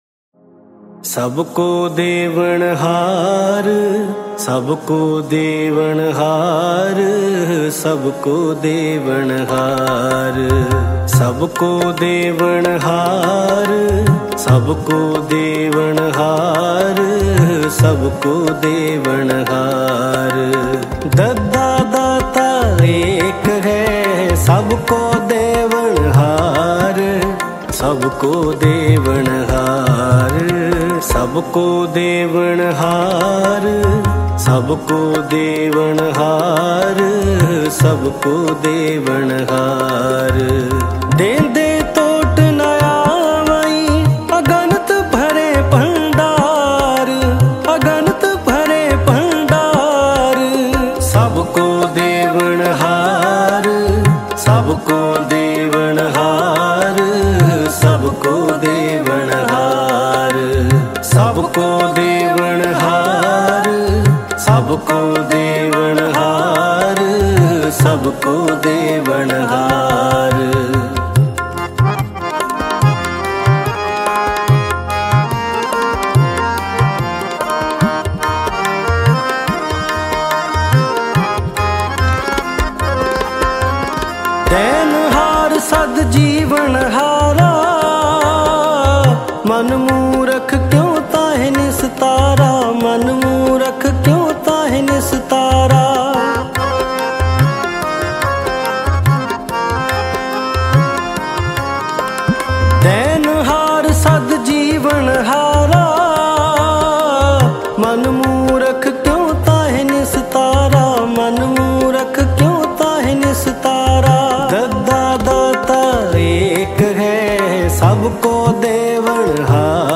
Mp3 Files / Gurbani Kirtan / 2025-Shabad Kirtan / Albums /